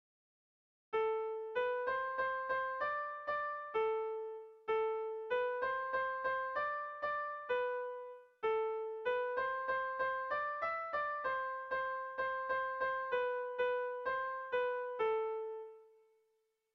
Sehaskakoa
A1A2A3